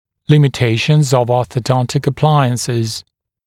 [ˌlɪmɪ’teɪʃnz əv ˌɔːθə’dɔntɪk ə’plaɪənsɪz][ˌлими’тэйшнз ов ˌо:сэ’донтик э’плайэнсиз]пределы возможностей ортодонтических аппаратов